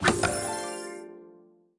Media:ArcherQueen_evo2_dep.wav 部署音效 dep 在角色详情页面点击初级、经典、高手和顶尖形态选项卡触发的音效